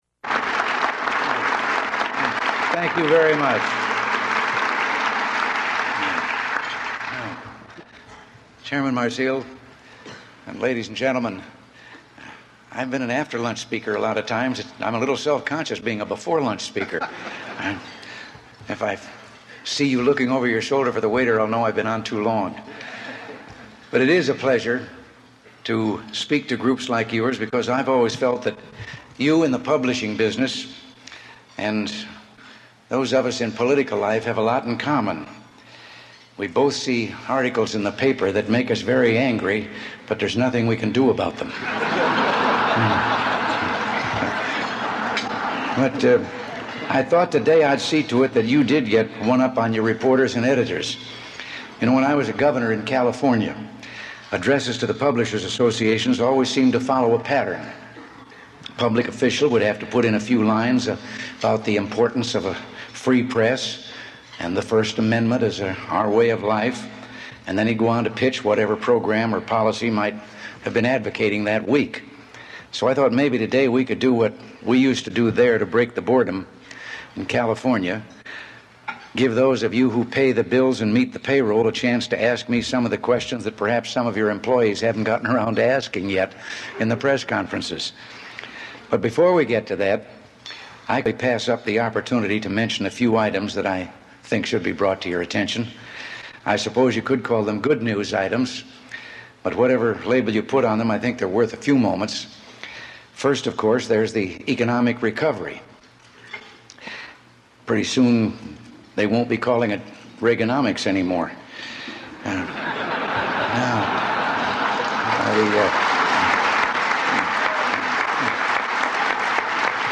U.S. President Ronald Reagan addresses the Newspaper Publishers' Association